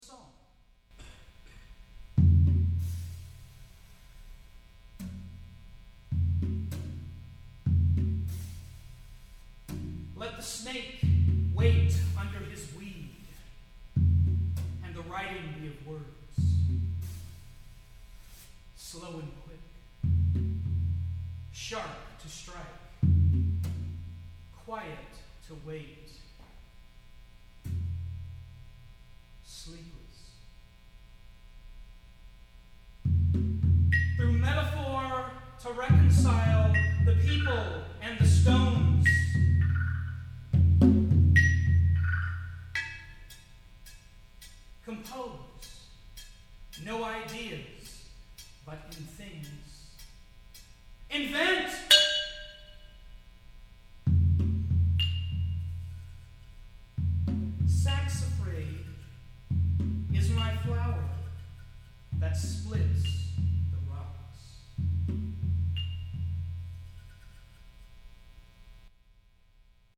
for a speaking/intoning solo percussionist